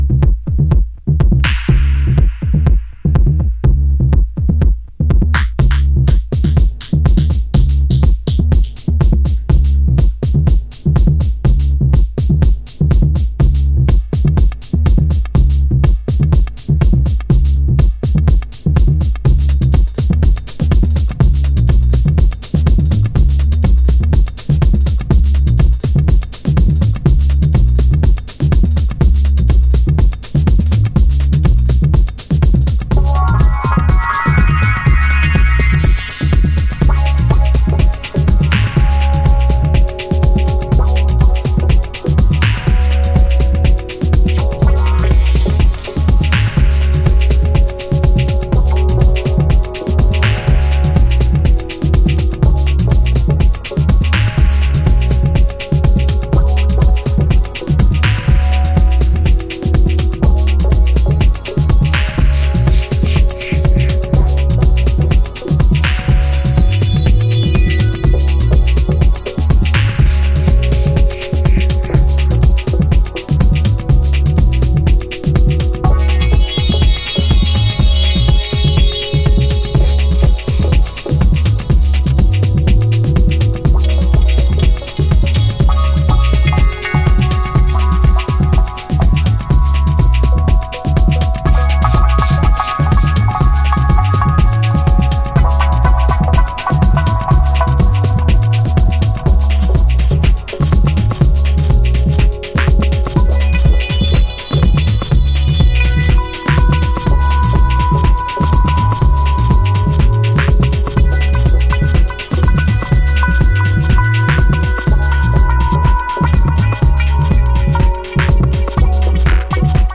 House / Techno
低いところ露骨にウネルベースラインとトリッピーなシンセワークで淡々とグルーヴしてくれるタフで頼りになるハウス。